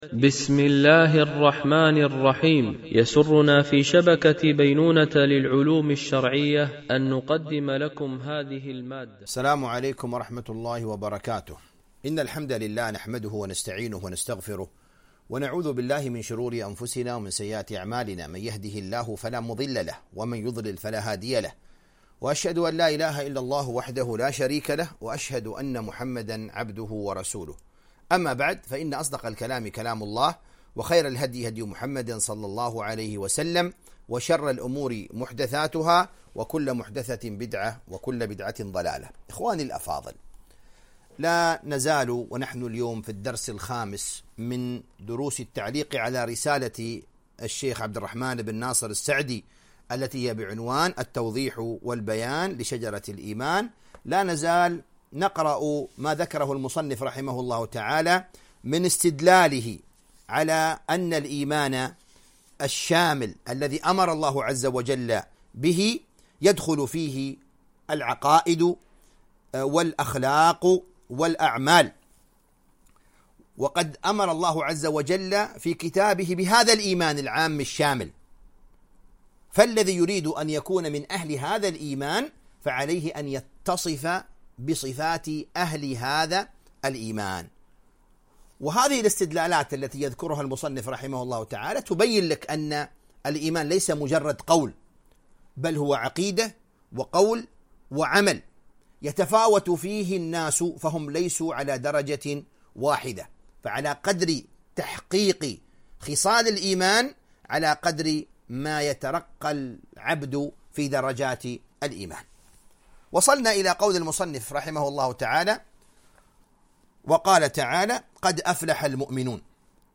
التعليق على كتاب التوضيح والبيان لشجرة الإيمان ـ الدرس 05